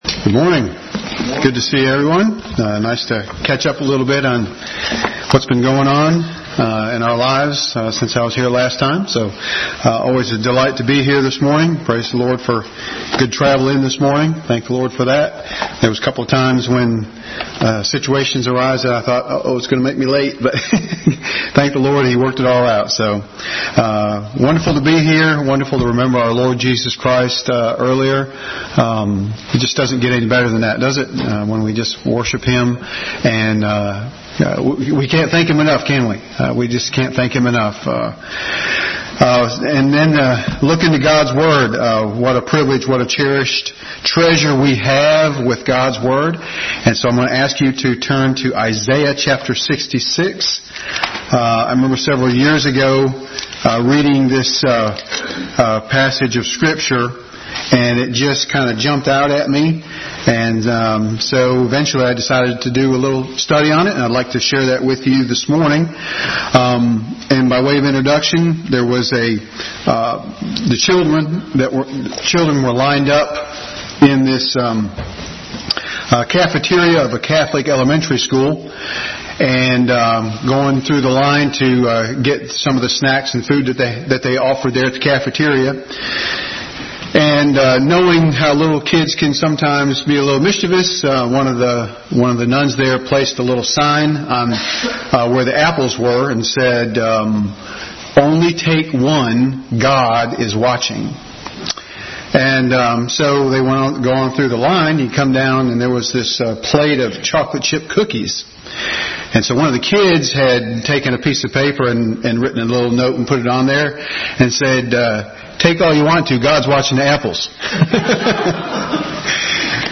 Bible Text: Isaiah 66:1-2, 2 Chronicles 36:16, Genesis 15:5, 19:17, 26, Psalm 119:6-15, 1 Samuel 2:30, Deuteronomy 10:13, Psalm 86, Isaiah 57:15 | Adult Sunday School message.